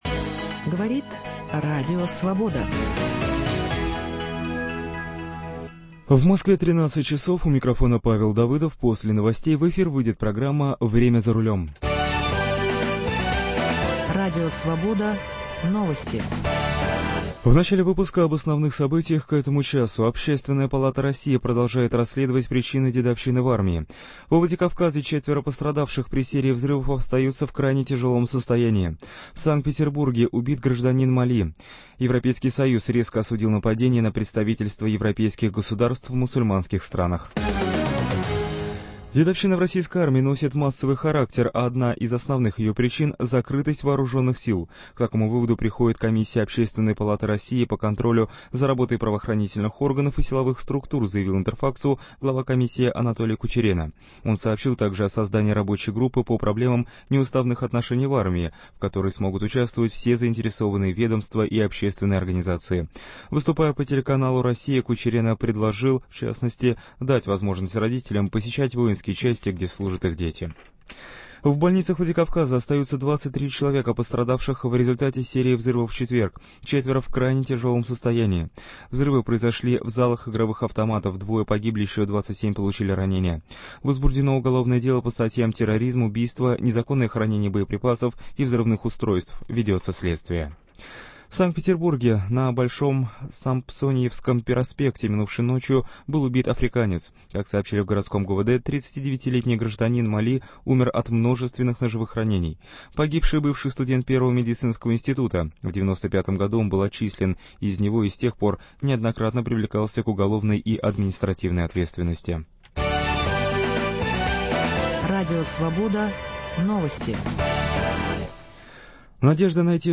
По воскресеньям через раз в прямом эфире - из наших Московского и Екатеринбургского бюро.